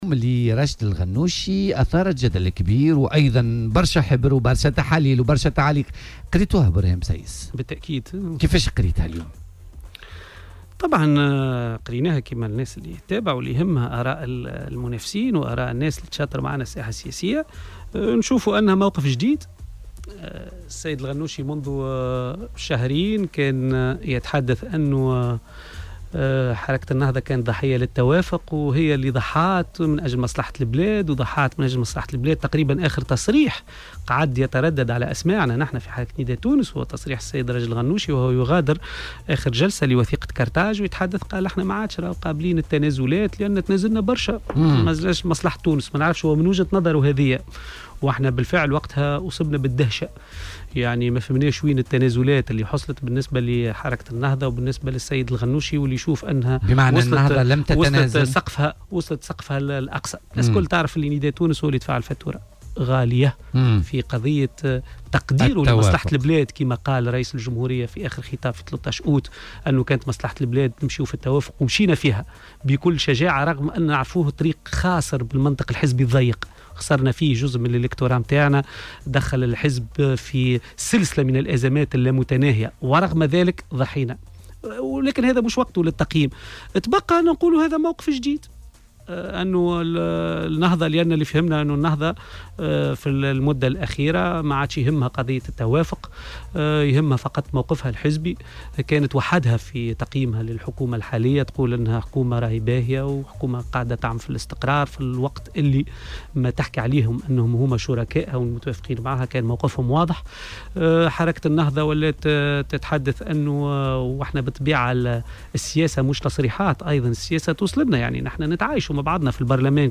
وقال ضيف "بوليتيكا" على "الجوهرة اف أم" إن راشد الغنوشي كان أعلن في وقت سابق عدم تقديم المزيد من التنازلات قبل الحديث اليوم عن استعداده للتنازل والتوافق.